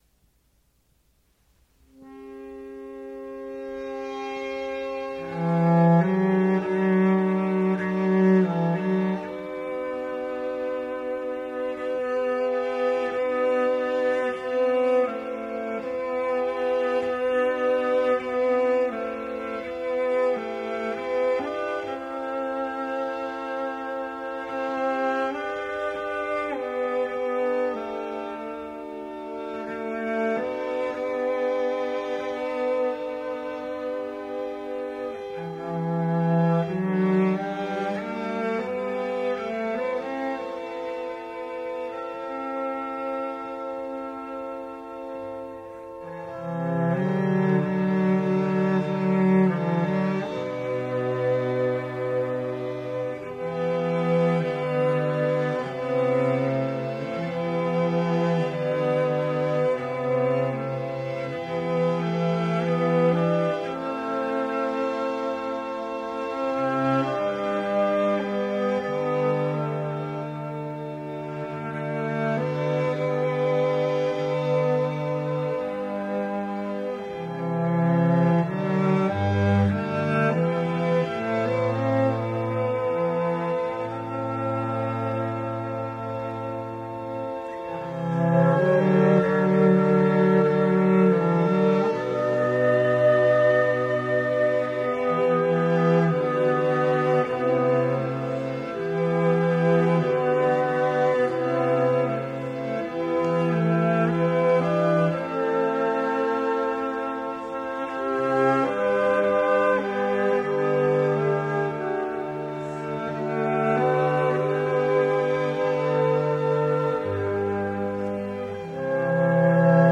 British female group
meditative arrangements